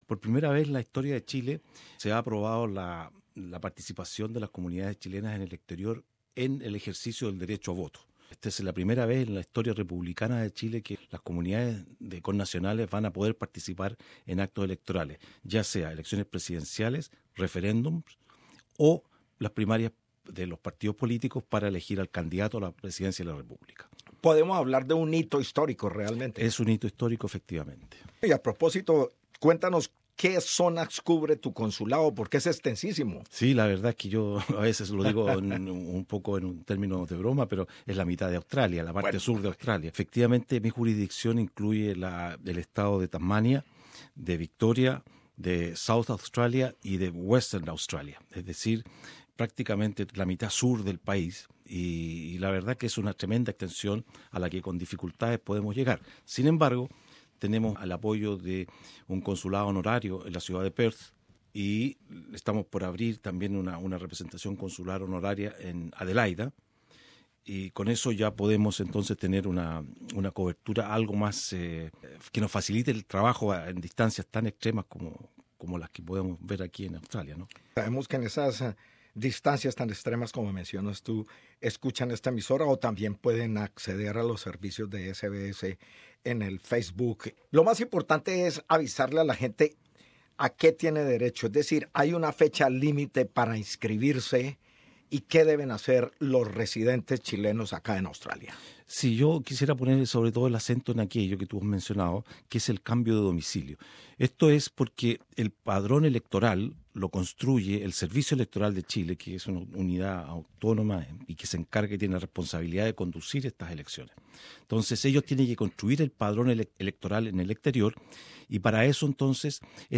Escuchamos al cónsul general de Chile radicado en Melbourne, Gabriel Jara, que comenta sobre este hecho histórico Share